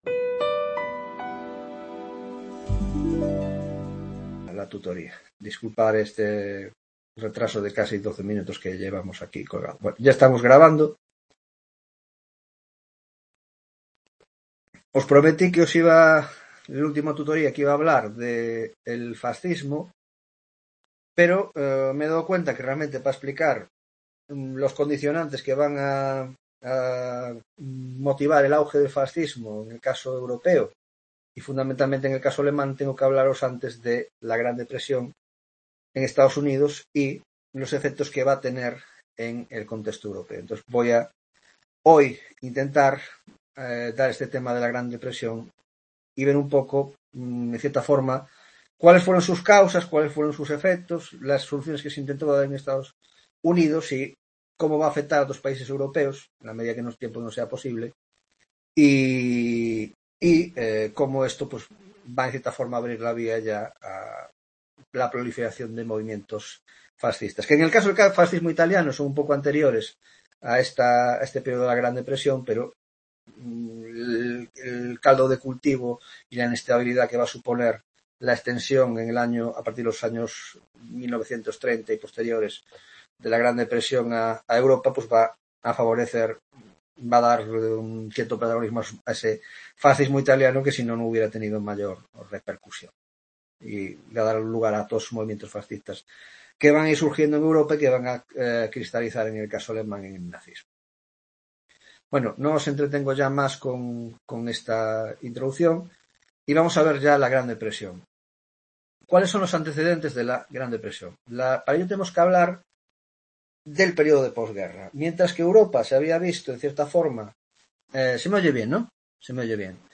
10ª tutoría de Historia Contemporánea